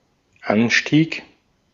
Ääntäminen
Synonyymit swell wave rush roar billow inrush jerk Ääntäminen US US : IPA : /sɝdʒ/ UK : IPA : /sɜːdʒ/ Haettu sana löytyi näillä lähdekielillä: englanti Käännös Konteksti Ääninäyte Substantiivit 1.